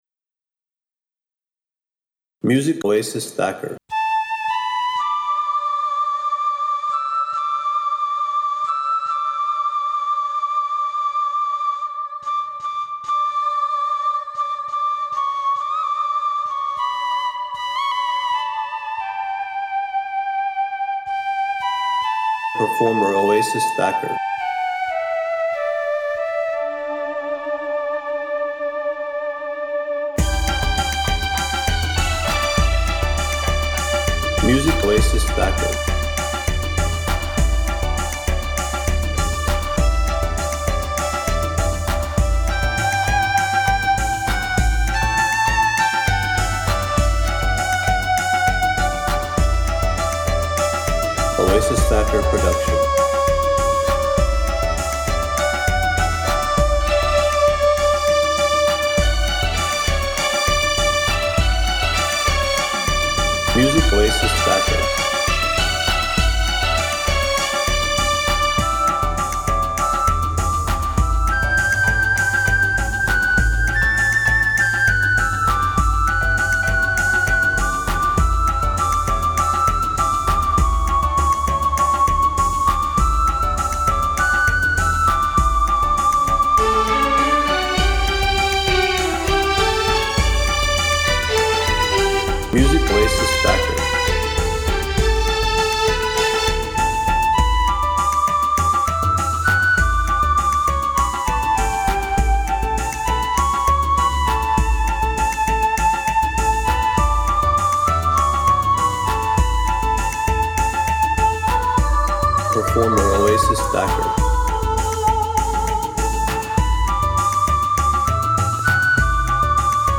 BOLLYWOOD CLASSICS
REMIX